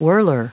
Prononciation du mot whirler en anglais (fichier audio)
Prononciation du mot : whirler